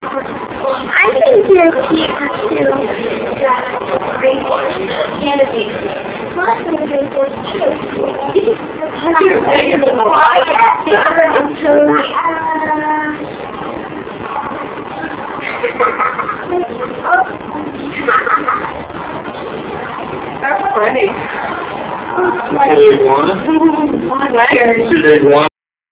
These are a series of short clips from a VHS video shot at the Erie Zoo, Erie Pennsylvania in the late 1980's The videos are in real video format.
Marmoset
marmoset1.ram